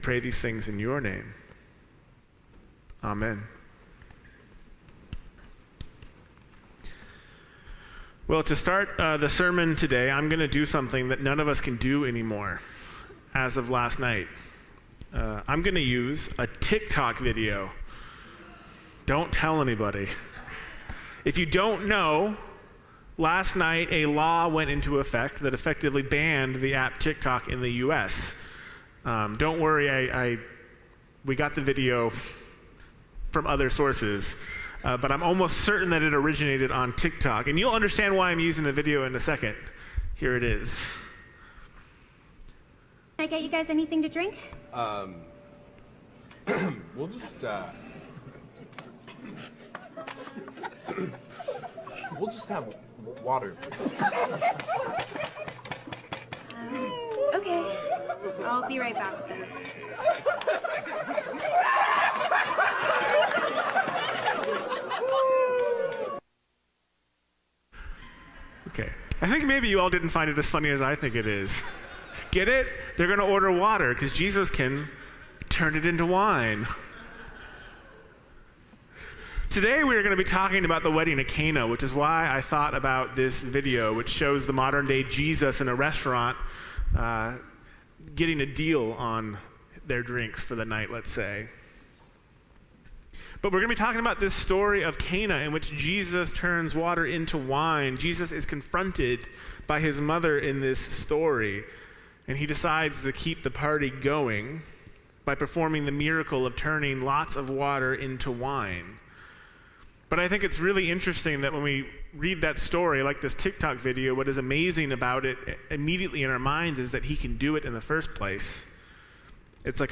Sermons | First Christian Church